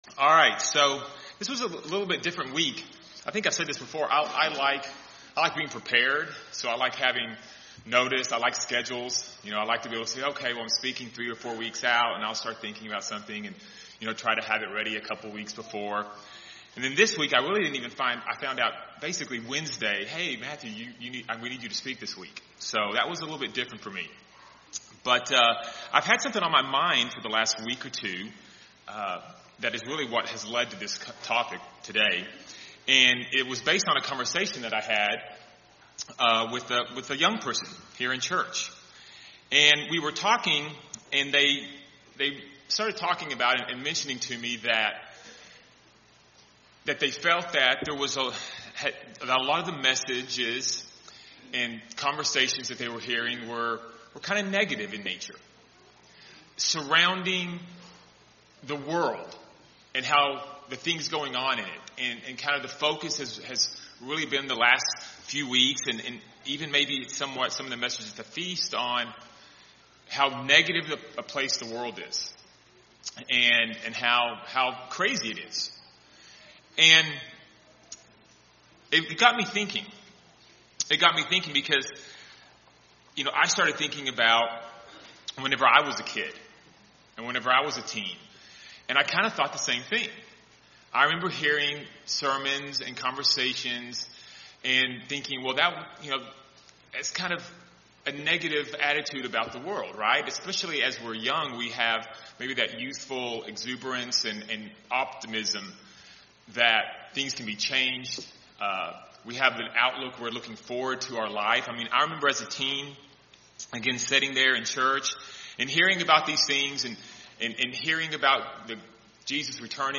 With so much going on in the world around us, it is very easy for our thoughts to be negative and for our light to shine less brightly. In the sermon, we will talk about strategies that are in the Bible that help us stay positive while the world around us becomes even more out of control.